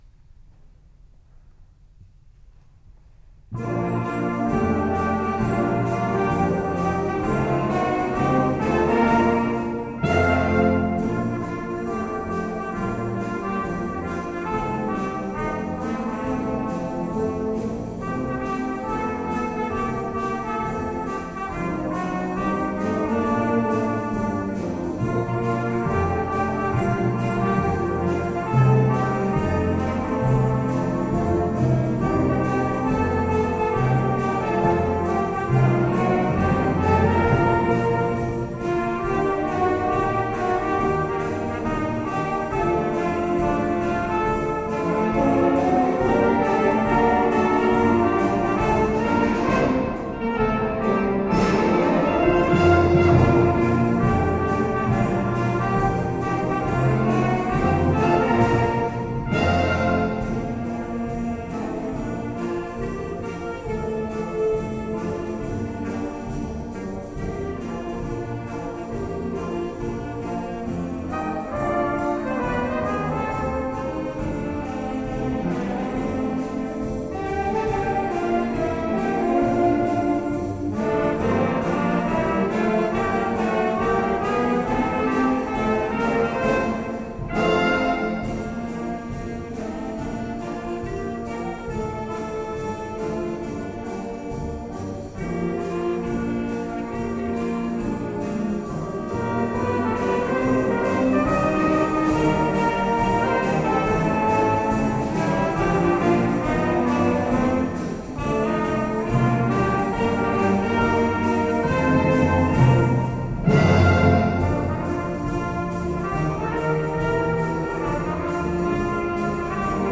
BANDA MUSICALE
Concerto di Natale 2011